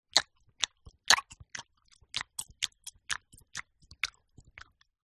Звуки поедания еды
Вкушает, когда жует